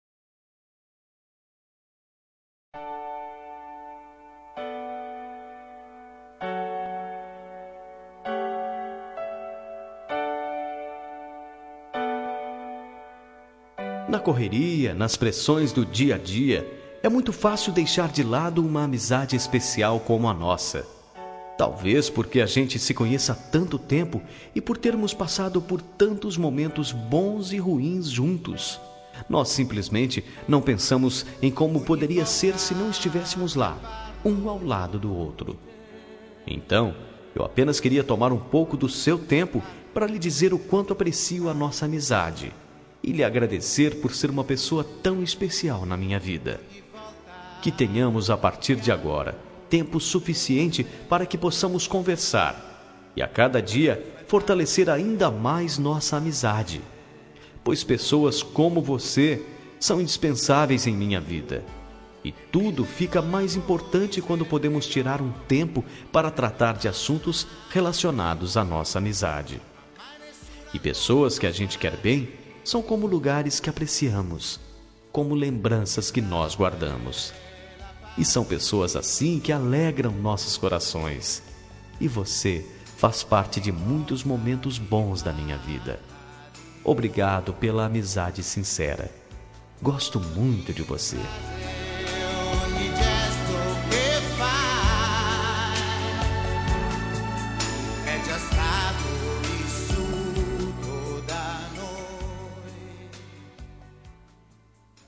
Telemensagem de Amizade – Voz Masculina – Cód: 107
107-amizade-masc.m4a